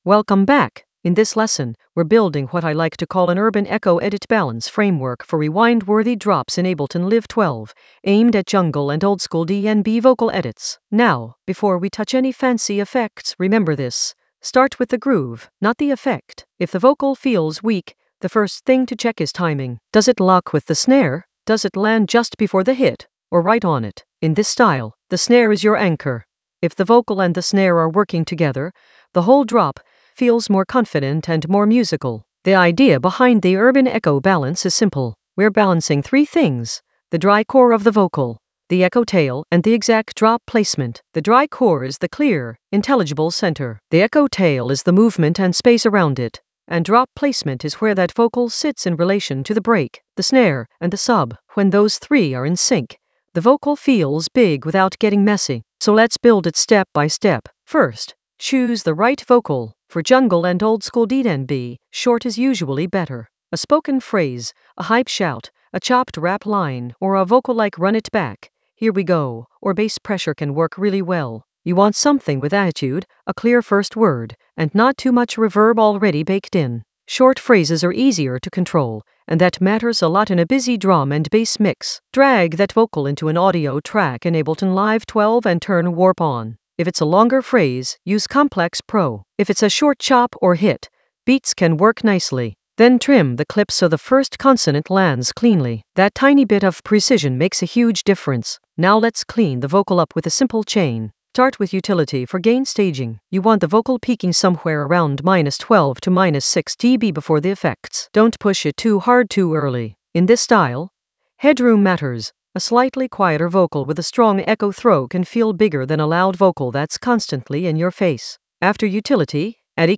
An AI-generated beginner Ableton lesson focused on Urban Echo edit balance framework for rewind-worthy drops in Ableton Live 12 for jungle oldskool DnB vibes in the Vocals area of drum and bass production.
Narrated lesson audio
The voice track includes the tutorial plus extra teacher commentary.